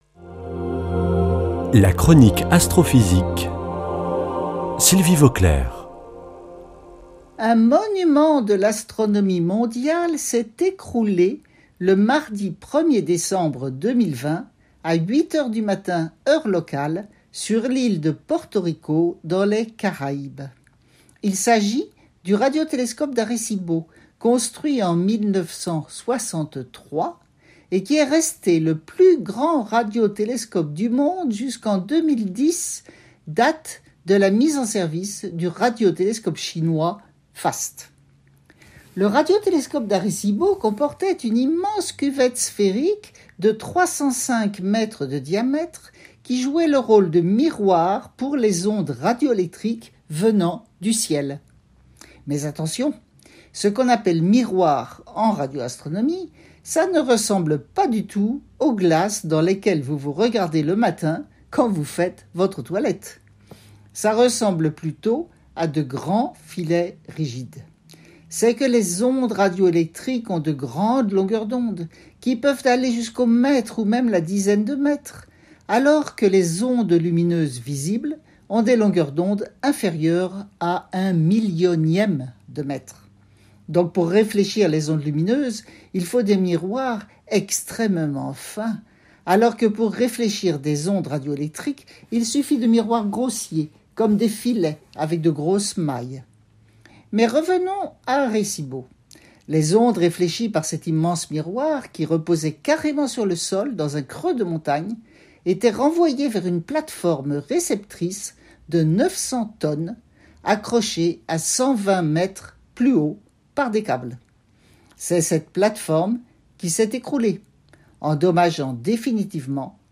vendredi 23 juillet 2021 Chronique Astrophysique Durée 3 min
Une émission présentée par